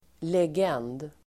Uttal: [leg'en:d]